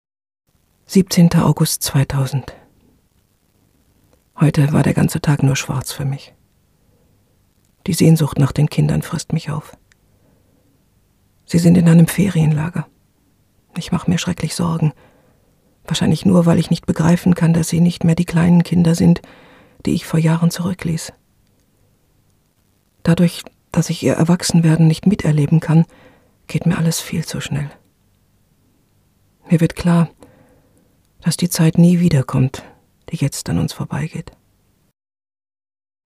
Sprachaufnahmen
Unsere Sprachkammer und hoch empfindliche Mikrofone, sowie verschiedenste Sprecher/ innen stehen Ihren Sprachaufnahmen zur Verf�gung.
Tonstudio Sound Universe Sprachaufnahmen.mp3